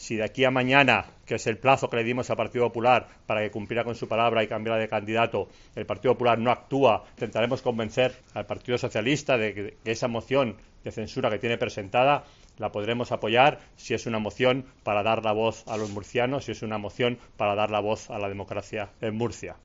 Villegas ha insistido en una rueda de prensa en la sede del partido en que el PP debe "mover ficha" mañana, cuando expira el plazo que Ciudadanos le dio al presidente murciano Pedro Antonio Sánchez (PP) para que abandone su cargo o se nombre a otro candidato para gobernar la región.